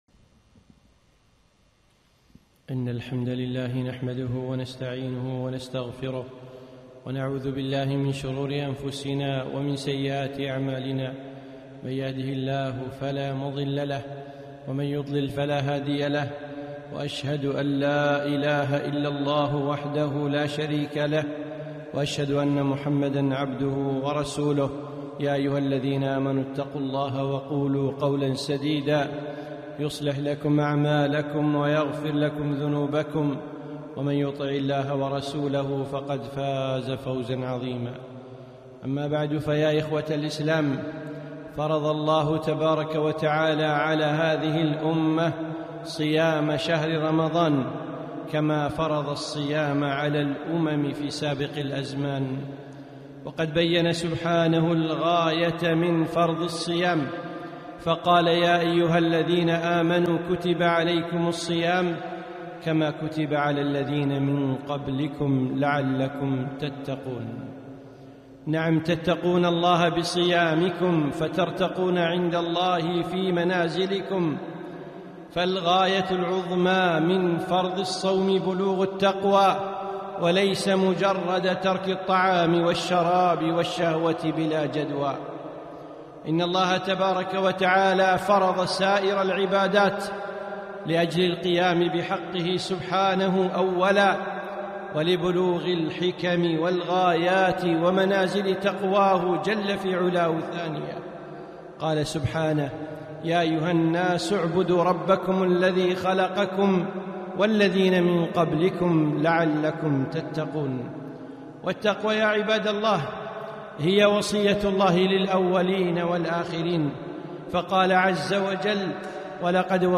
خطبة - لعلكم تتقون